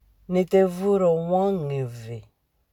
Lecture et prononciation